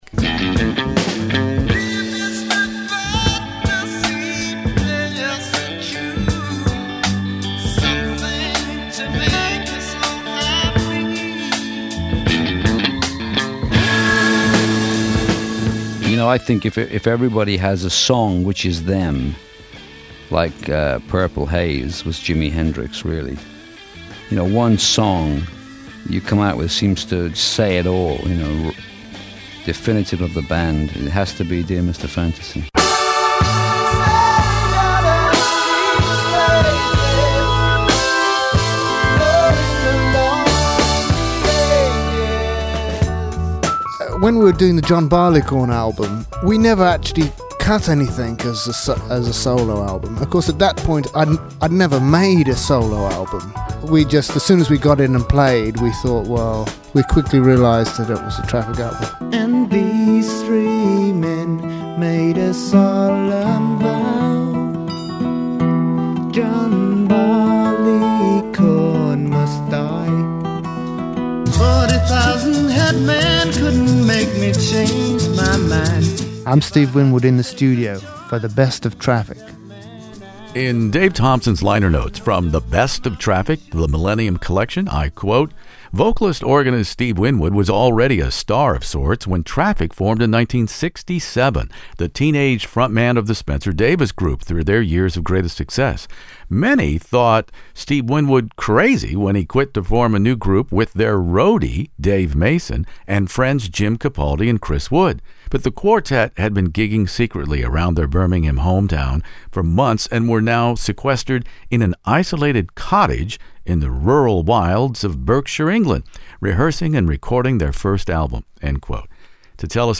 The love and affection between my guests, Steve Winwood and the late Jim Capaldi, are clearly on display in my classic rock interview exploring one of rock’s most eclectic bands, Traffic, and their debut Mr. Fantasy in late 1967.